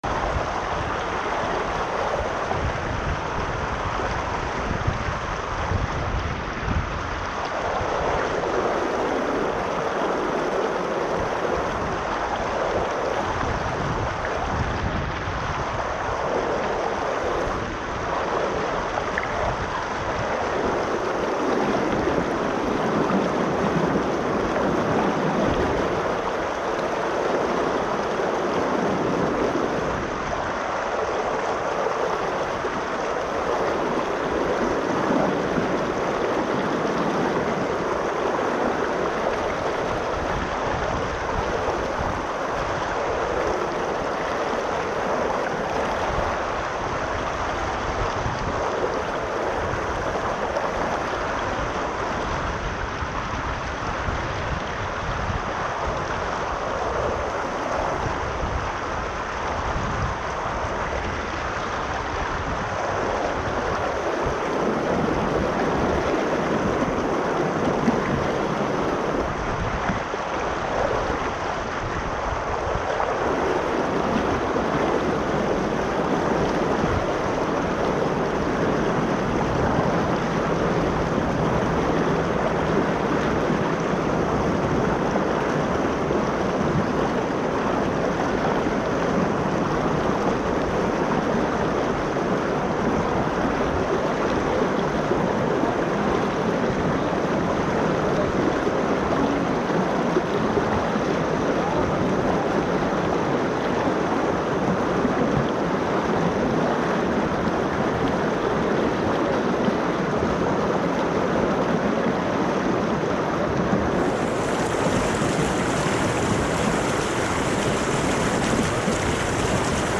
Audio from Sound of a River During Ice Melt YouTube Video Although many of us understand the year to be divided into four seasons, a more discerning approach to the times of the year attunes us to the squidgy, melty zone between winter and spring. As many bodies start to roust themselves from winter rest and others change up their pace to match the brighter mornings, the FEELed Lab invites you for a gentle walk to listen to the thaw.